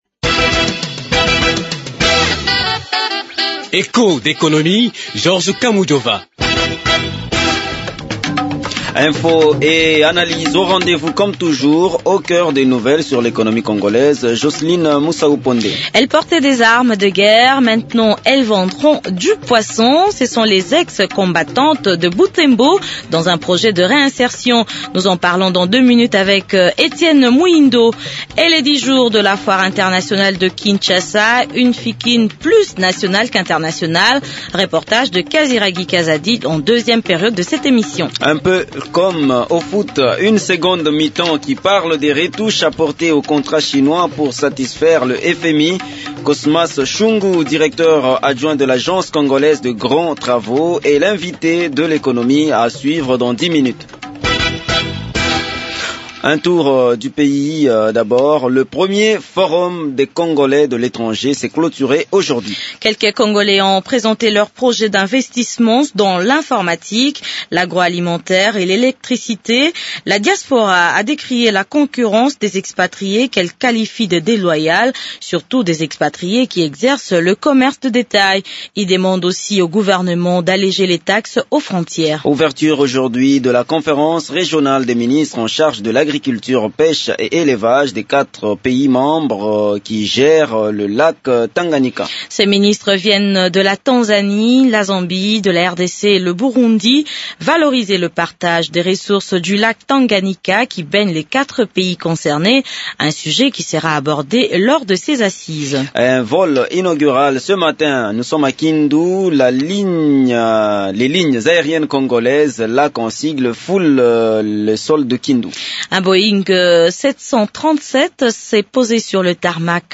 Regard sur les dix jour de la foire internationale de Kinshasa, une foire plus nationale qu’internationale, c’est le reportage à suivre.